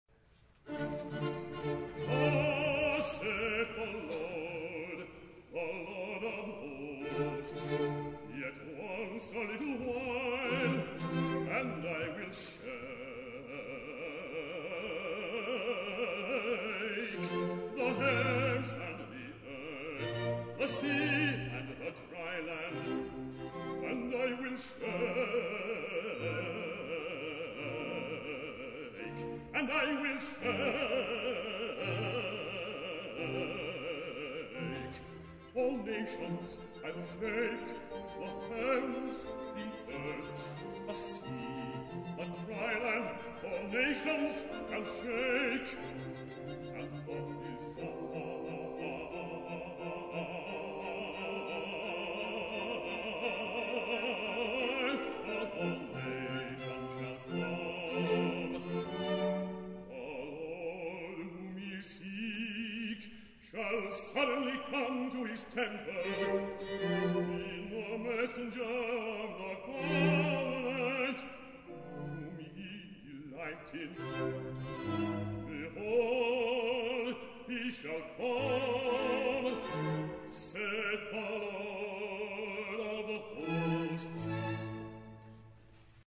Opera Demos